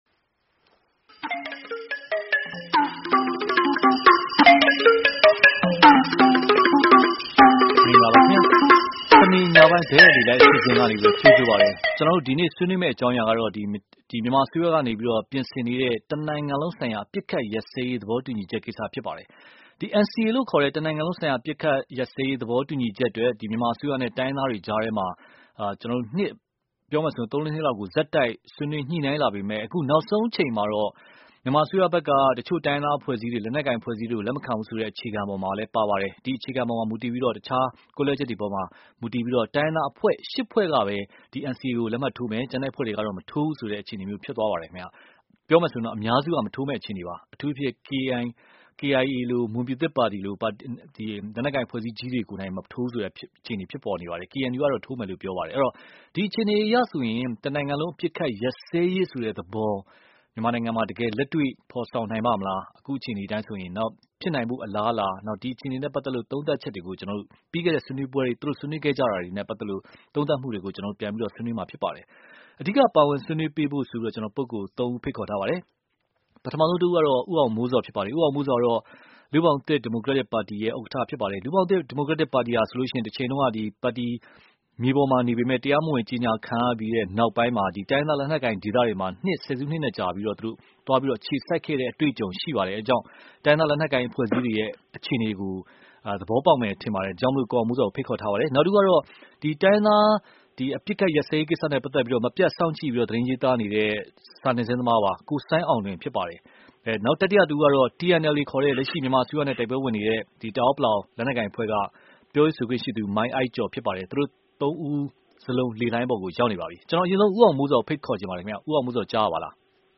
စနေနေ့ညတိုက်ရိုက်လေလှိုင်းအစီအစဉ်